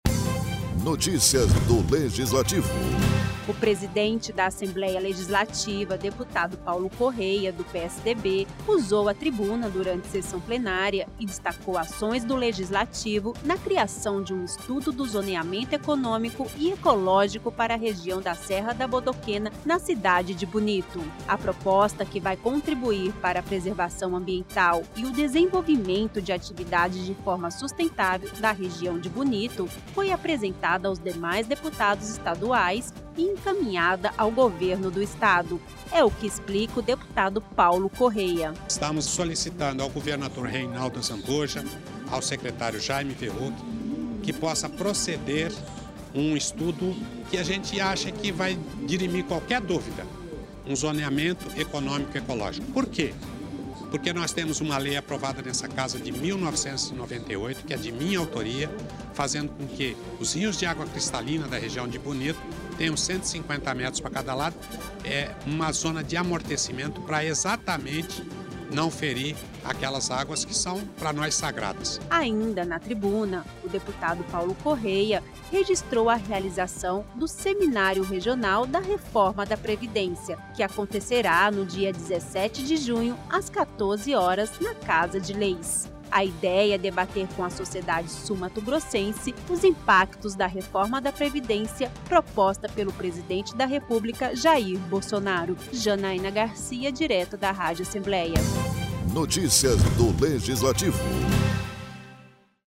O presidente da Assembleia Legislativa de Mato Grosso do Sul, deputado Paulo Corrêa, do PSDB, registrou durante sessão plenária desta terça-feira 28, a importância da realização do estudo do Zoneamento Ecológico-Econômico para a região da Serra da Bodoquena.